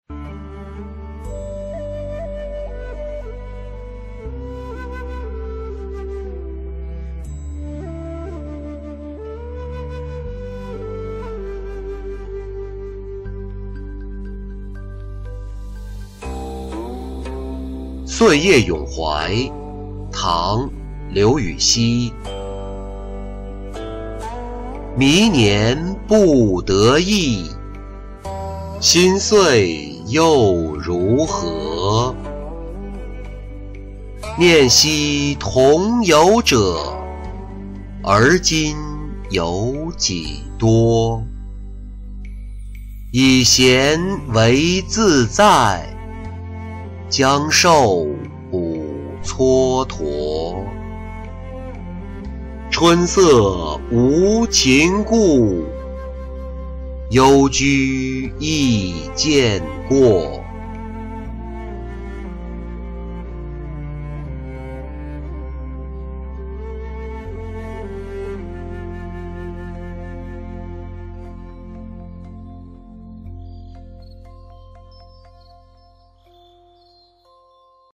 岁夜咏怀-音频朗读